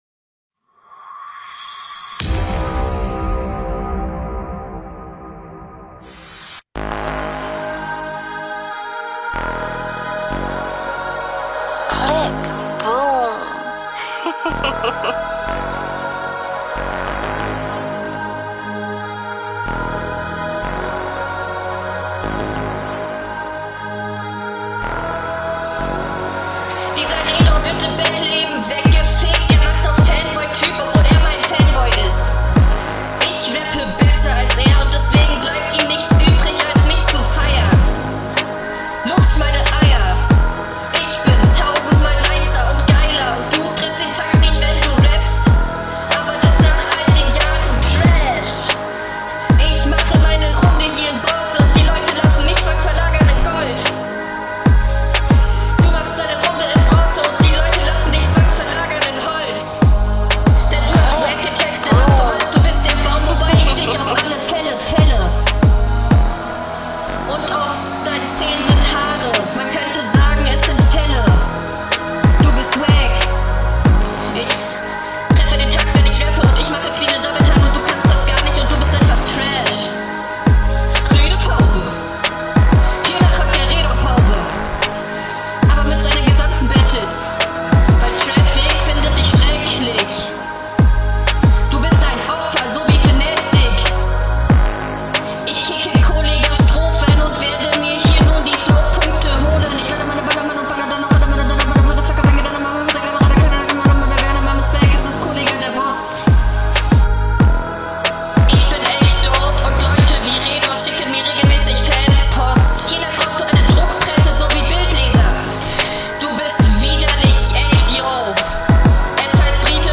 üb mal beim freestlye den takt zu treffen